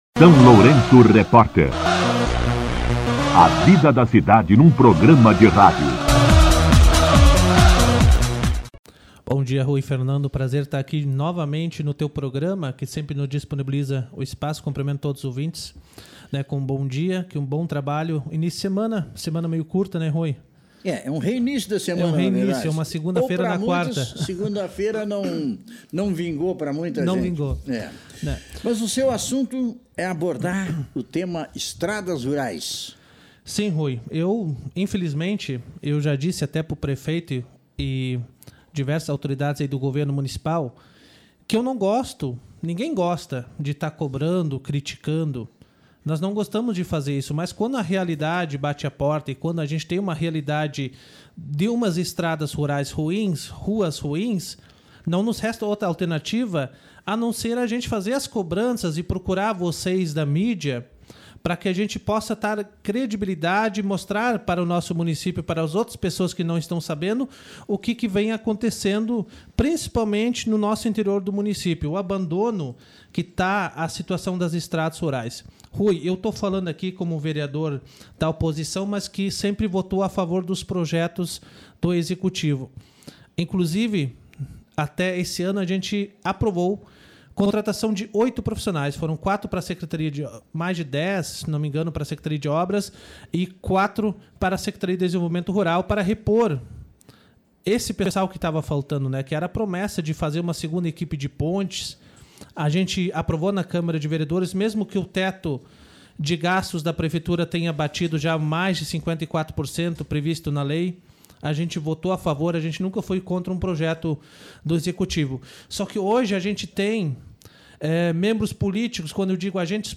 Entrevista com O vereador Willian Holz
O vereador Willian Holz (PL) concedeu entrevista ao SLR RÁDIO na manhã desta quarta-feira (22) e afirmou haver indícios de possível impeachment do prefeito Zelmute Marten (PT), em razão do suposto descumprimento de leis municipais.